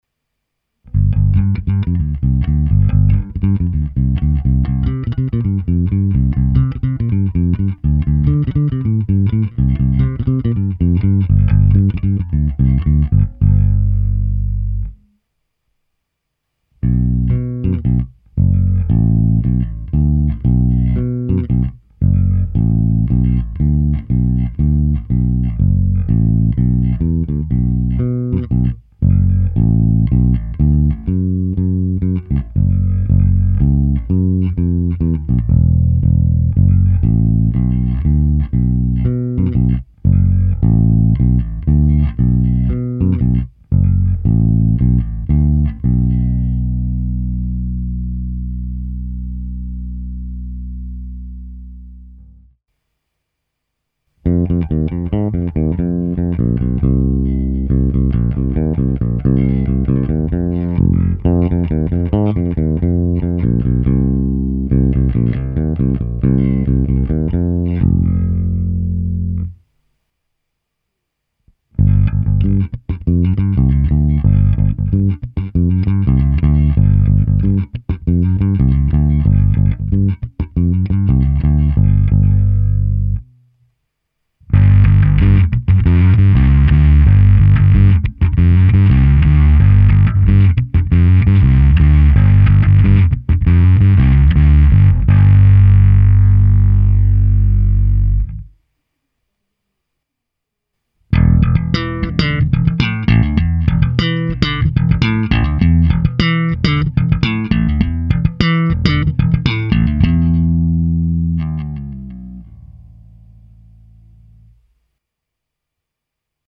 UKÁZKA modelu Elite. Basy a výšky nepatrně přidané, je to hrané přes kompresor TC Electronic SpectraComp a preamp Darkglass Alpha Omega Ultra se zapnutou simulací aparátu. V pořadí: krkový snímač, oba snímače, malinko upřednostněný kobylkový snímač, oba snímače bez a se zkreslením a nakonec slap. Je to s hlazenkami Thomastik Jazz Flat Wound JF344. Trochu mi to tam drnčí, pak jsem ještě trochu sešteloval dohmat, který je stále krásně nízký.